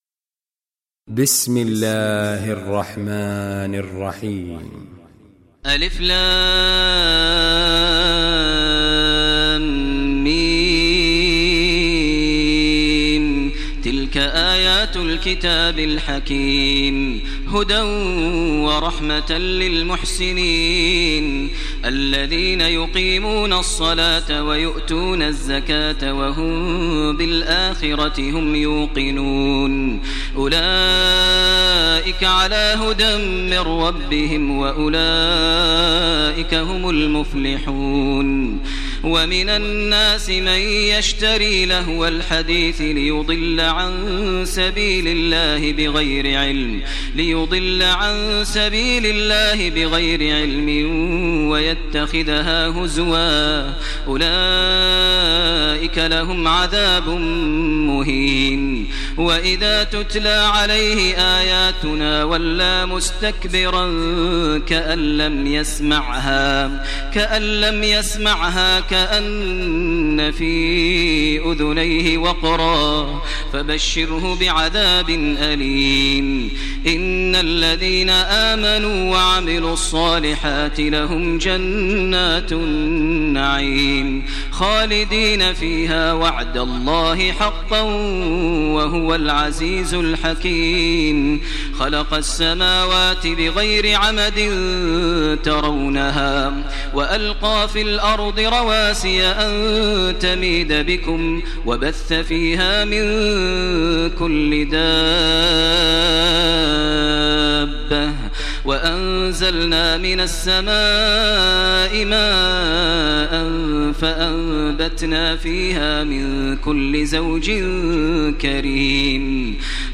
Surah Luqman Recitation by Maher al Mueaqly
Surah Luqman, listen online mp3 tilawat / recitation in Arabic recited by Imam e Kaaba Sheikh Maher al Mueaqly.